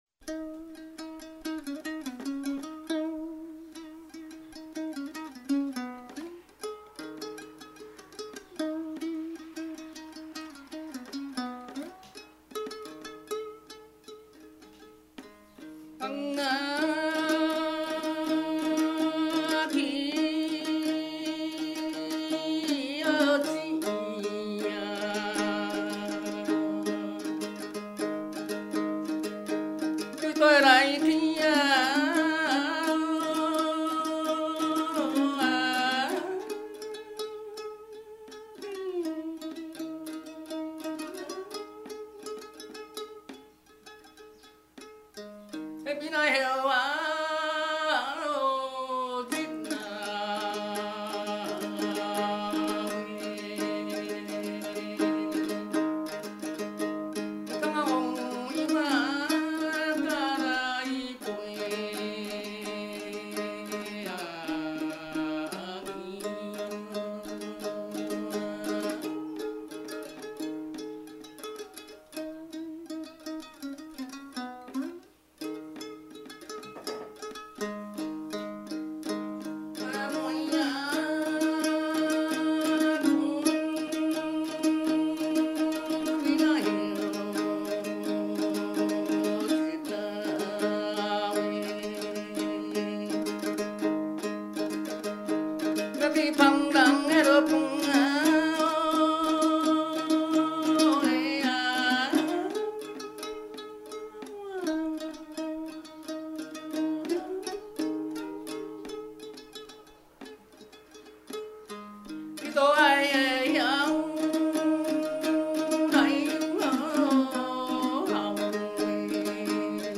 ◎制作群 ： 演出：月琴,壳仔弦｜
台湾民歌
18首原汁原味的素人歌声，在粗哑中满怀真性情，那是上一代的回忆，这一代的情感，下一代的宝藏！